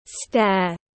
Cầu thang tiếng anh gọi là stair, phiên âm tiếng anh đọc là /steər/.
Stair /steər/